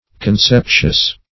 Conceptious \Con*cep"tious\